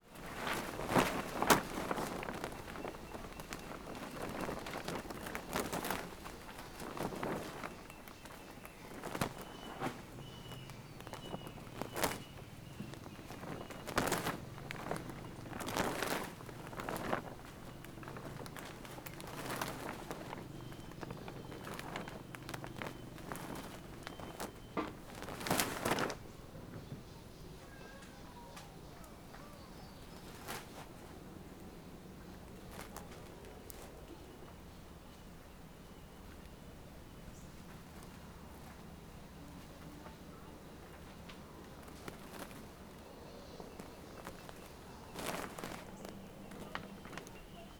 CSC-16-019-OL- Lona de plastico movimentando se com vento vozerio.wav